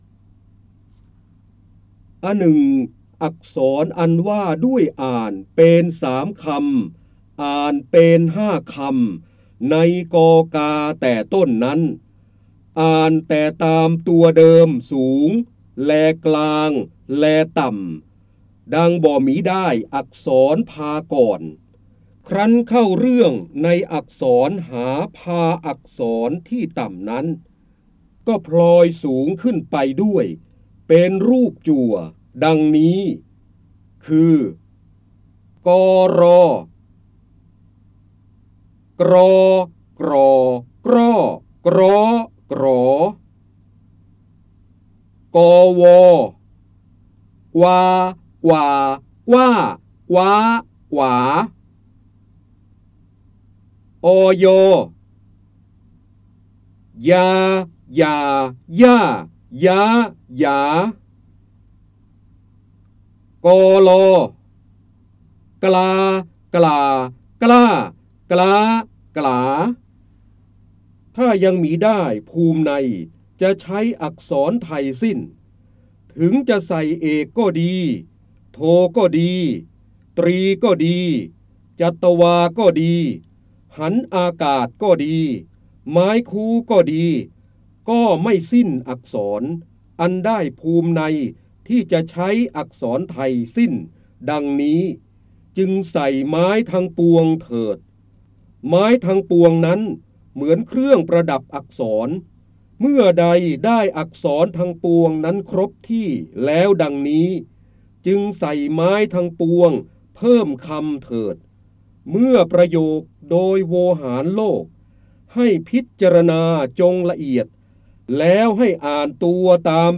156 111 ตัวอย่าง ดาวน์โหลด ส่ง eCard เสียงบรรยายจากหนังสือ จินดามณี (พระเจ้าบรมโกศ) อหนึ่งอักษรอันว่าด้วยอ่านเปน ๓ คำ ได้รับใบอนุญาตภายใต้ ให้เผยแพร่-โดยต้องระบุที่มาแต่ห้ามดัดแปลงและห้ามใช้เพื่อการค้า 3.0 Thailand .